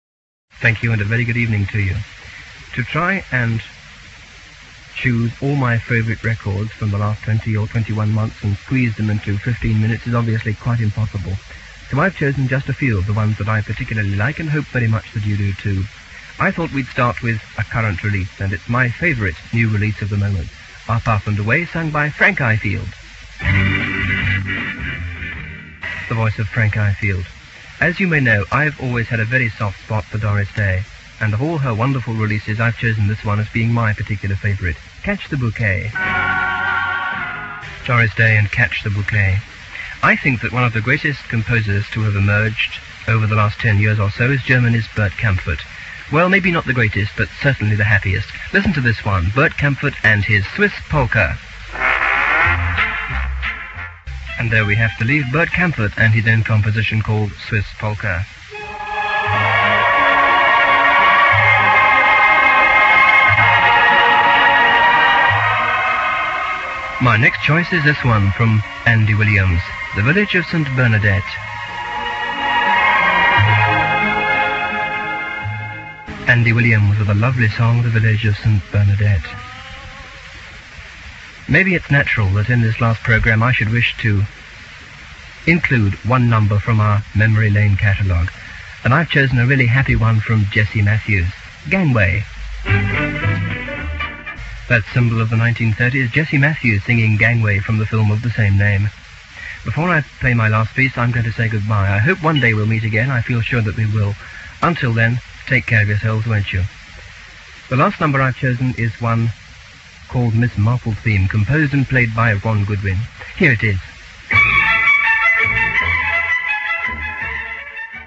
never-transmitted farewell show from Radio 390